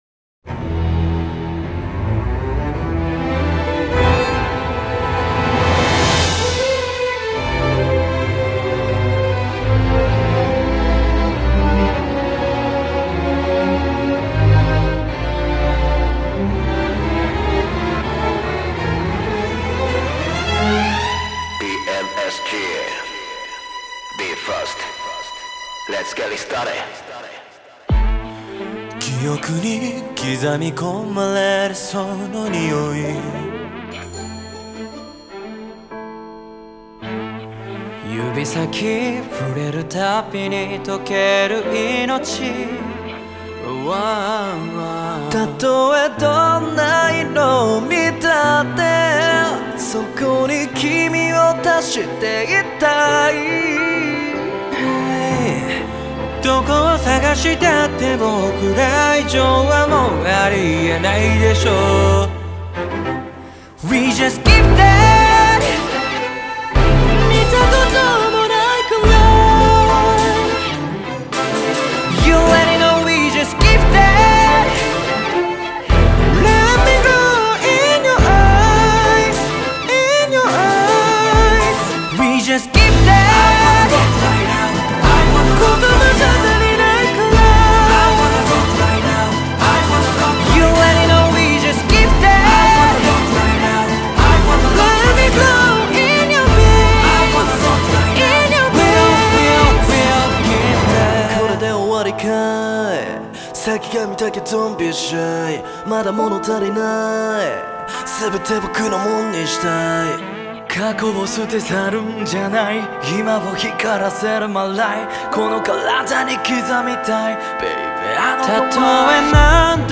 世界最高峰の音響を誇るシンフォニーホール「ミューザ川崎」で収録された。